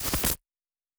pgs/Assets/Audio/Sci-Fi Sounds/Electric/Glitch 2_03.wav at master
Glitch 2_03.wav